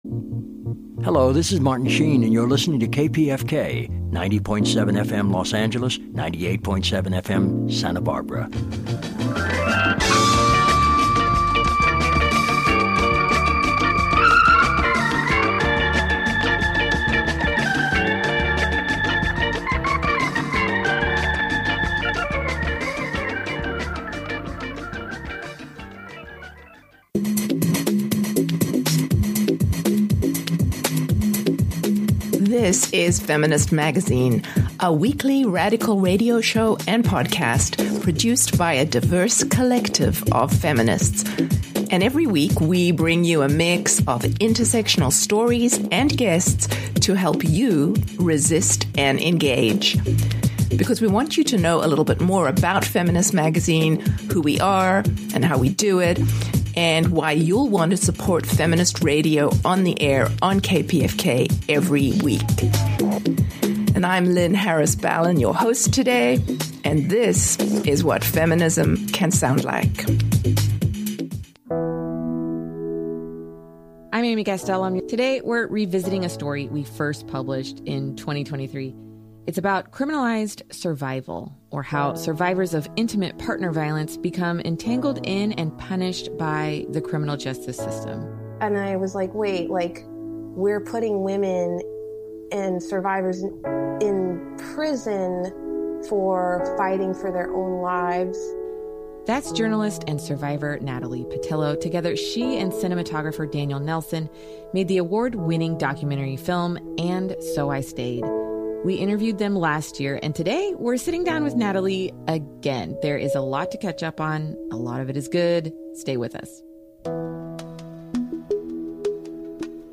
1 Interview with CA Attorney General Rob Bonta, Community College 'Ghost Students,' TV Talk, and More 1:39:05